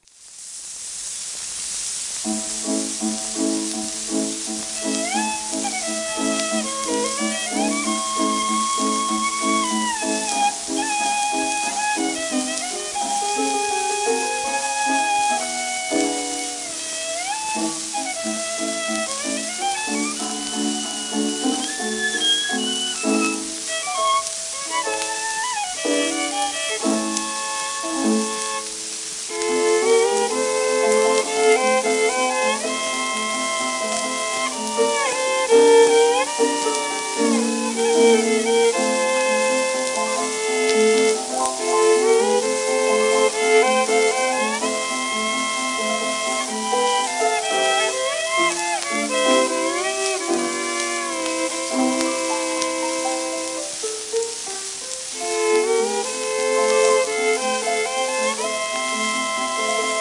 w/ピアノ
1908年頃英国録音、80rpmと思われる